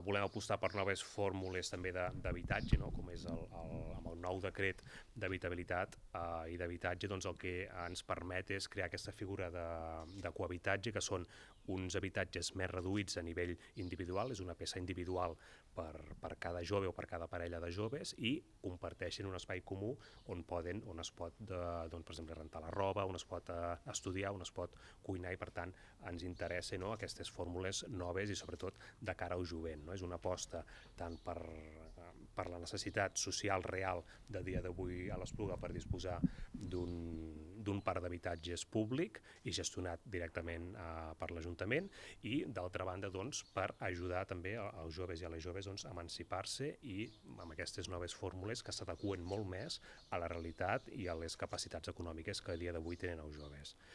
ÀUDIO: L’alcalde de l’Espluga de Francolí, Josep Maria Vidal, destaca la creació d’habitatges per joves